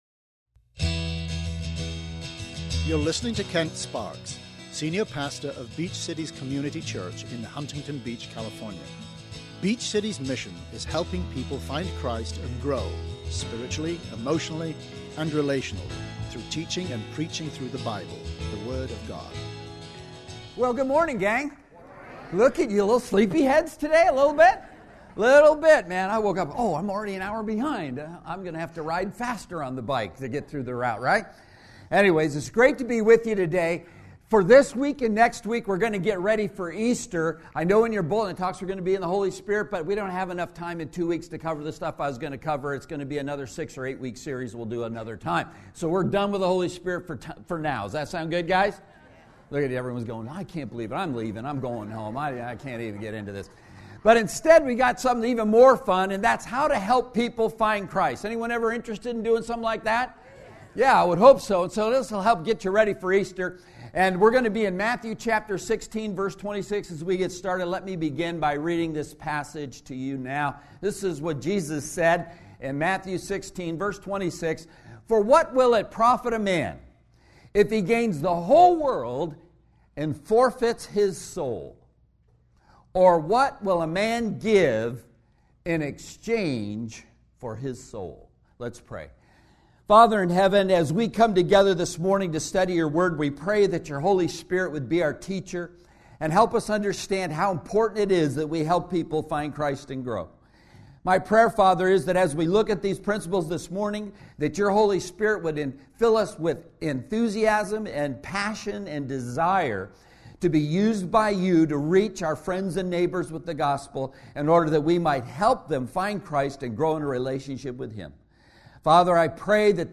SERMON AUDIO: SERMON NOTES: